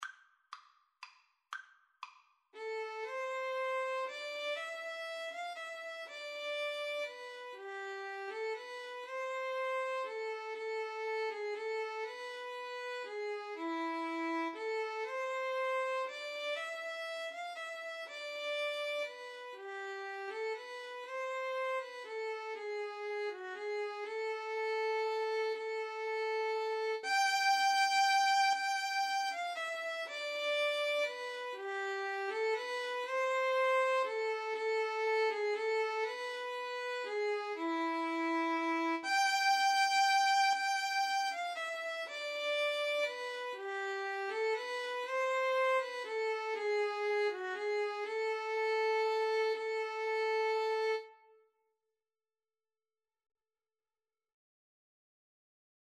Play (or use space bar on your keyboard) Pause Music Playalong - Player 1 Accompaniment transpose reset tempo print settings full screen
A minor (Sounding Pitch) (View more A minor Music for Violin-Viola Duet )
3/4 (View more 3/4 Music)